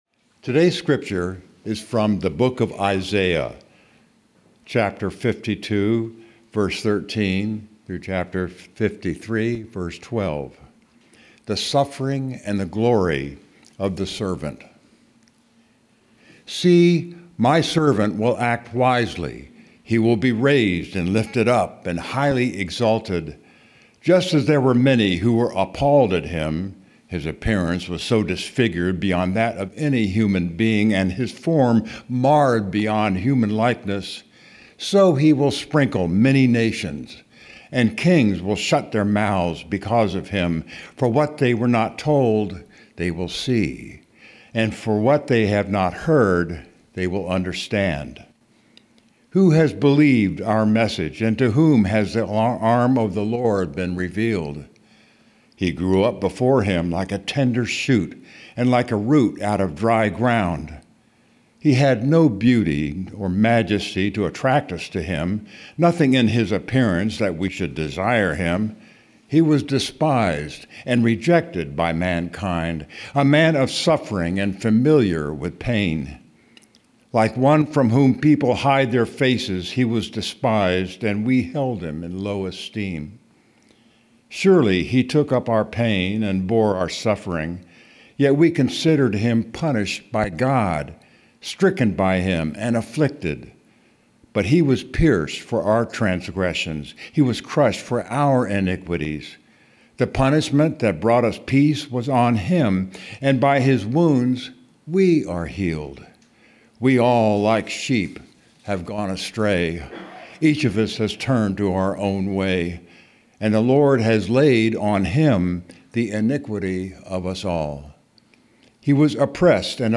February 15, 2026 Sermon Audio